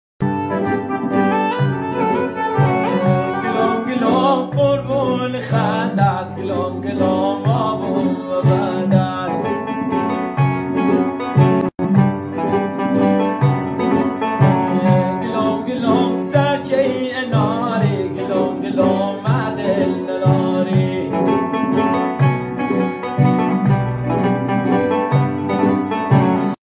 دانلود آهنگ لری